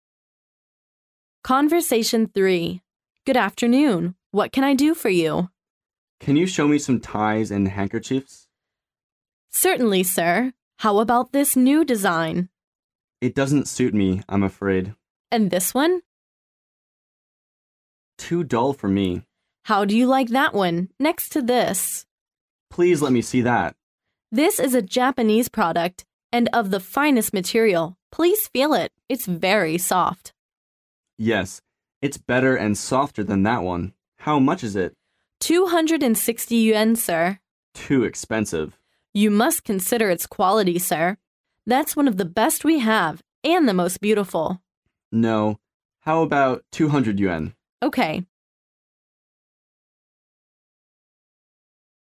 Conversation 3